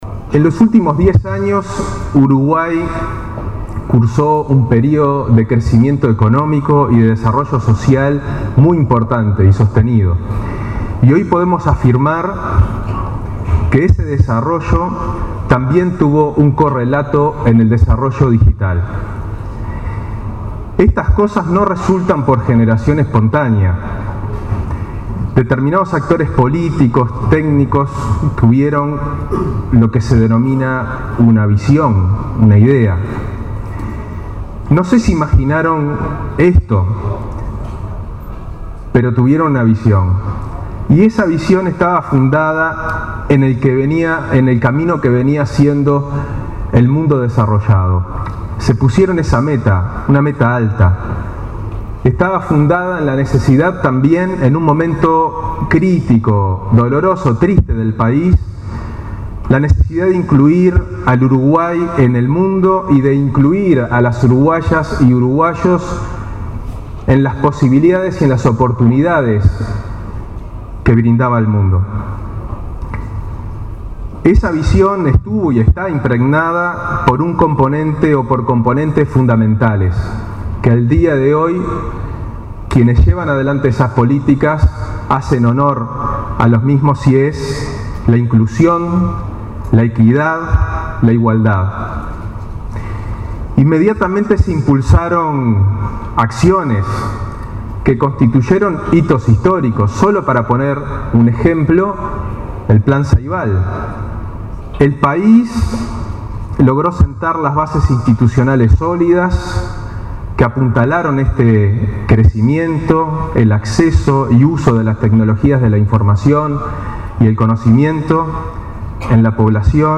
“En el Uruguay de hoy la tecnología ya no es un componente diferenciador entre ricos y pobres o jóvenes y adultos”, afirmó el prosecretario de Presidencia, Juan Andrés Roballo, en cierre de la celebración de los 10 años de la Agesic. La reducción casi total de la brecha digital, en el acceso y uso de la tecnología, es uno de los logros del Gobierno, expresó, y mencionó la implementación del Plan Ceibal como ejemplo de ello.